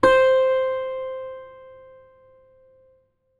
ZITHER C 3.wav